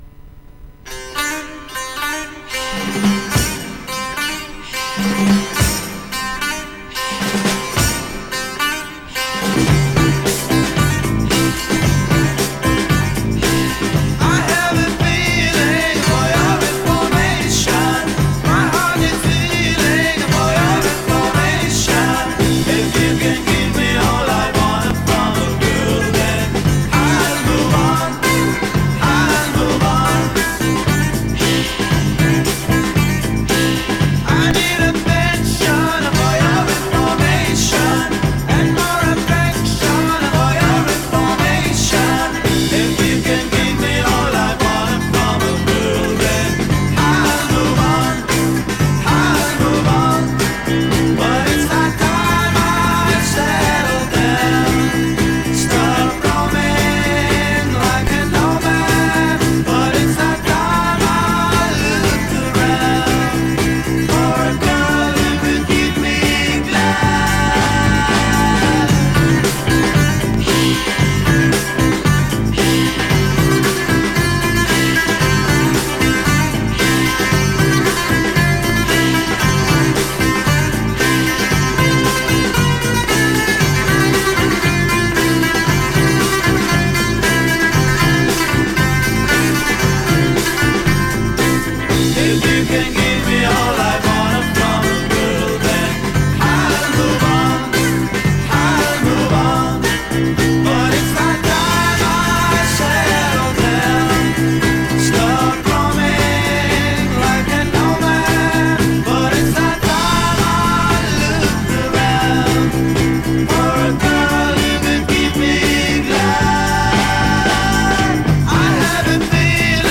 Ready for some Lebanese garage band pop?